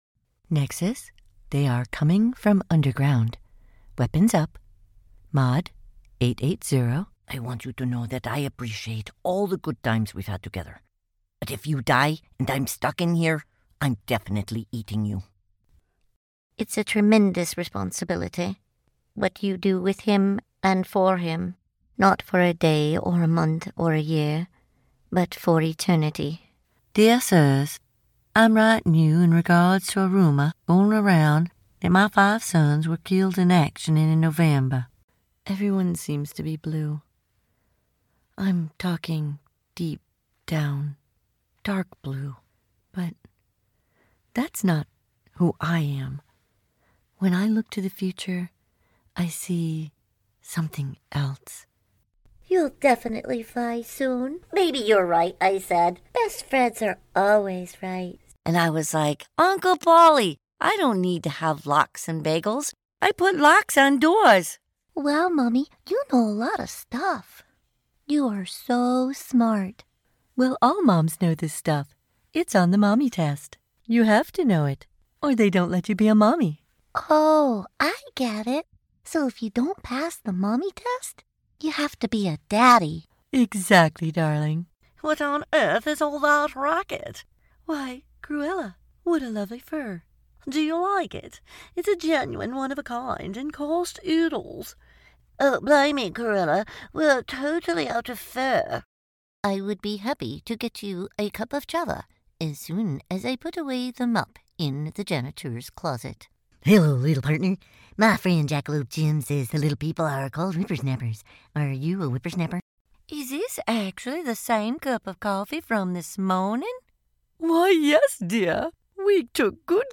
Looking for that perfect female voice for your video game, cartoon or audiobook?
Here’s a reel with a bunch of these characters!